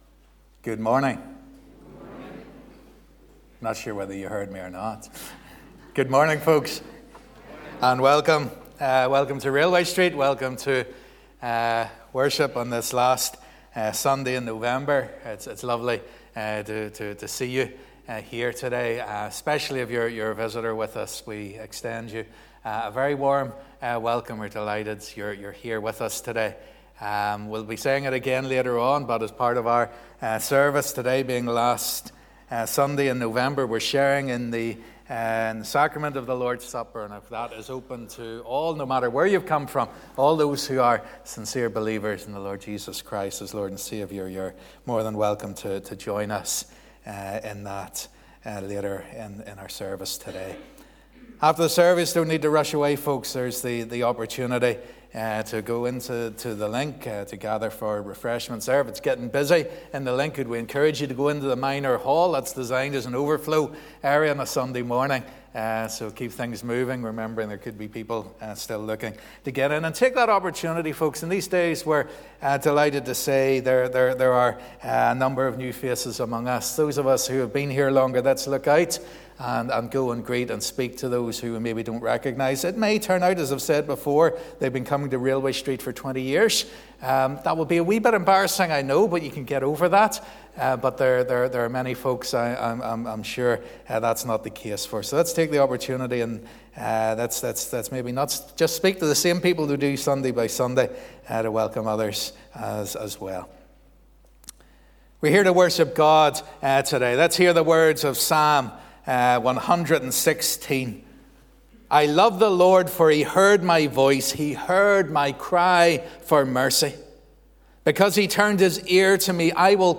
Live @ 10:30am Morning Service